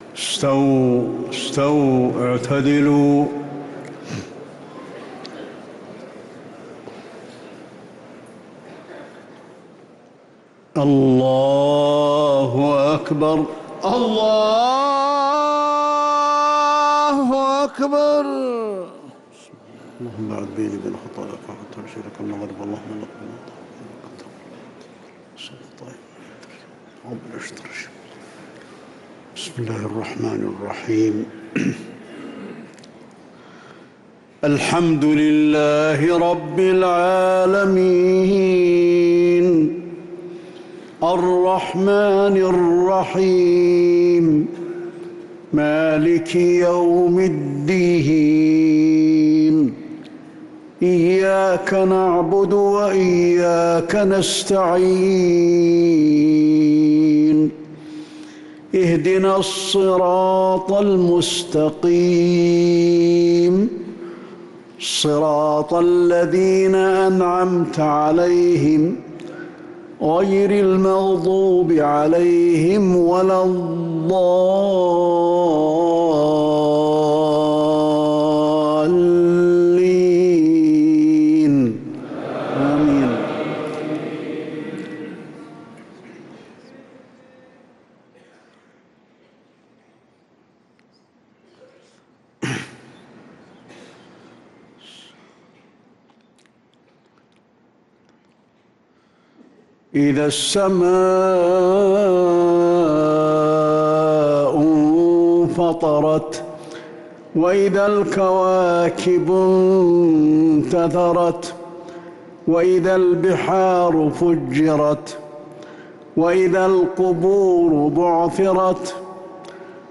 صلاة المغرب للقارئ علي الحذيفي 27 رمضان 1445 هـ
تِلَاوَات الْحَرَمَيْن .